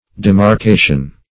Demarkation \De`mar*ka"tion\, n.